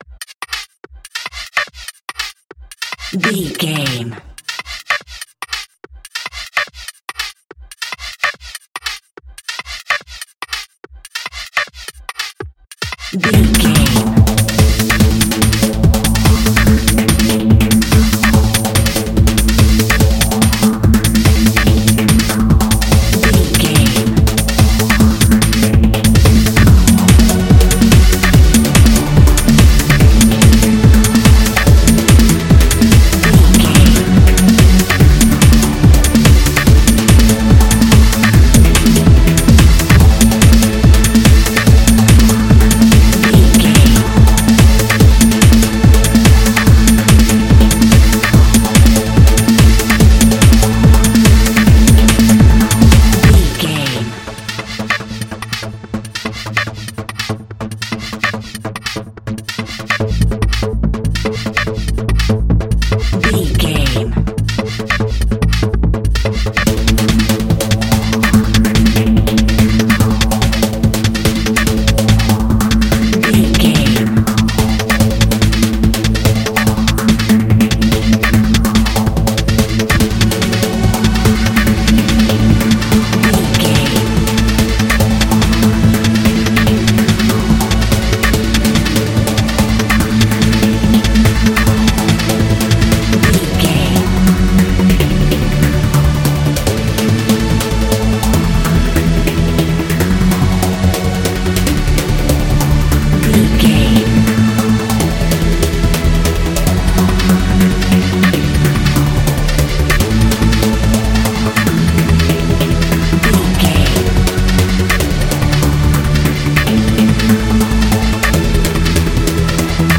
Ionian/Major
Fast
energetic
uplifting
hypnotic
drum machine
synthesiser
acid house
uptempo
synth leads
synth bass